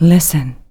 Pre-recorded Goddess
The following eight clips comprised the canned introduction that participants heard when the first entered Placeholder, in the Cave World.